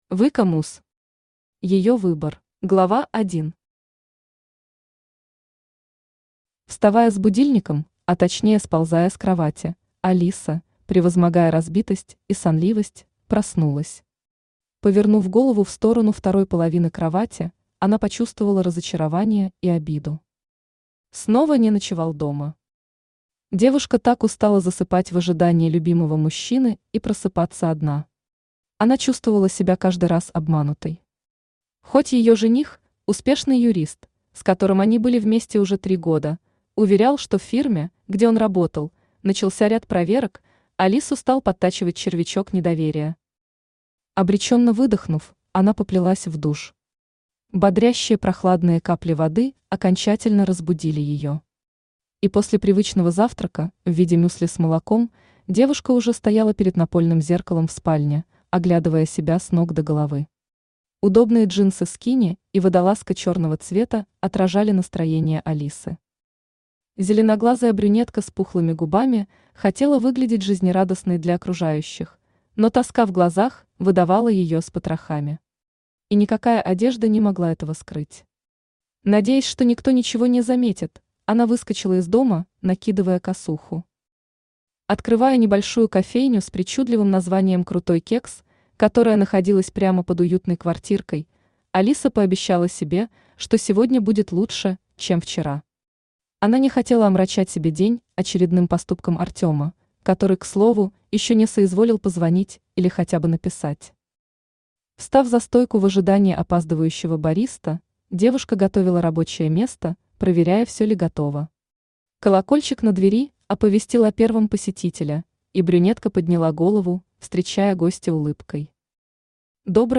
Aудиокнига Её выбор Автор Vicky Muss Читает аудиокнигу Авточтец ЛитРес.